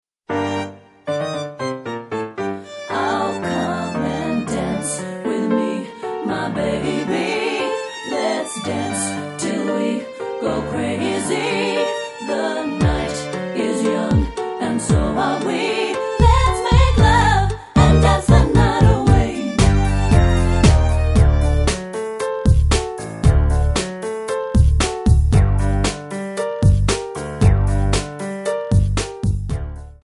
Audio Backing tracks in archive: 9793